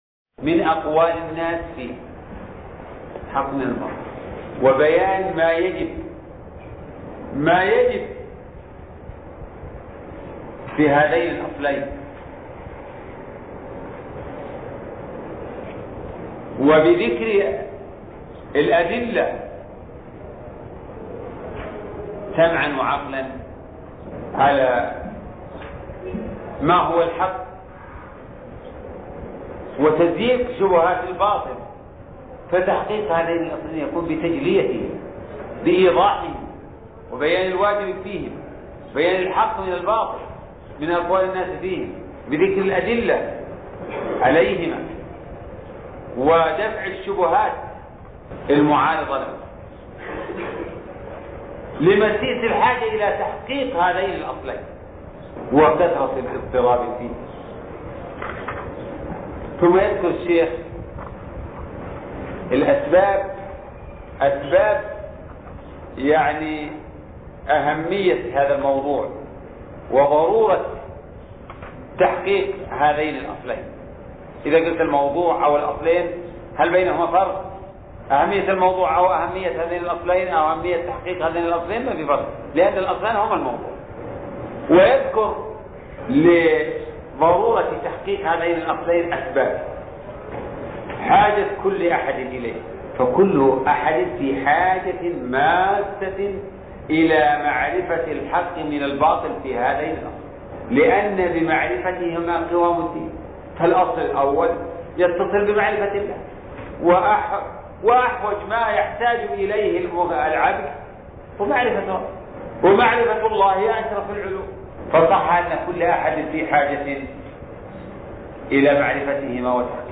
شرح الرسالة التدمرية (2) الدرس الثاني